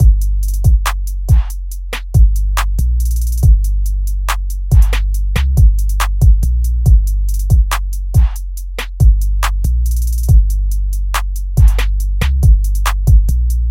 卡萨布兰卡 140bpm硬鼓循环
描述：Hard Trap Drum Loop, 140bpm, Key C